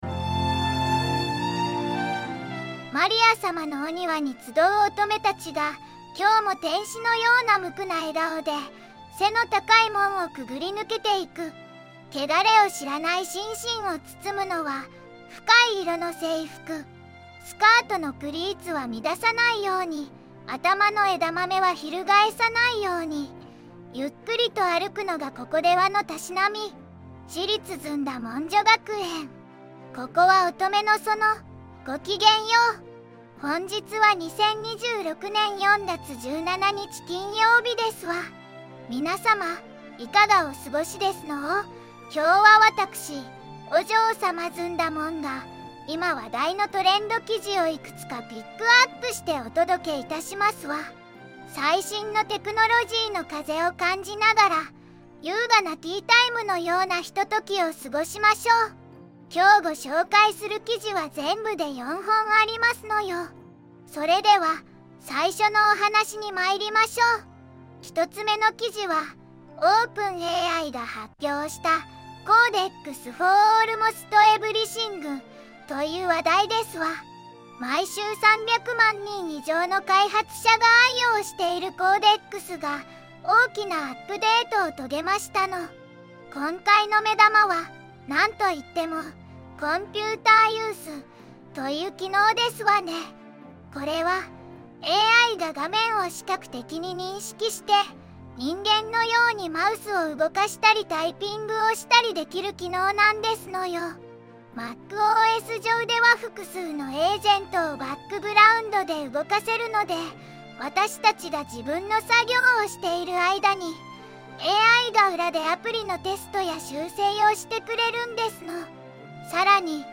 VOICEVOX:ずんだもん